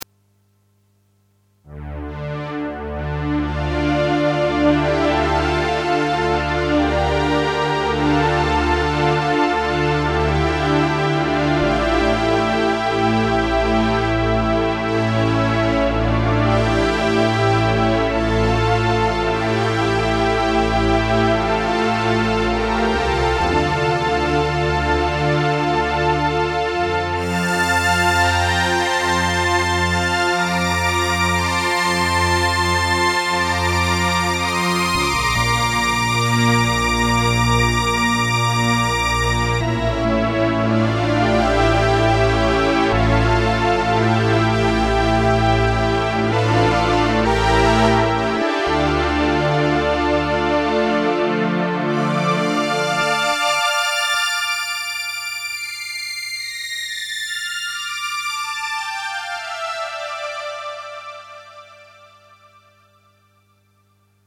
Roland JX-10 Polyphonic Synthesizer.
SyncPWMString - A string synth type sound made using the hard sync capability to create a pulse width modulation.
SyncPWMString.mp3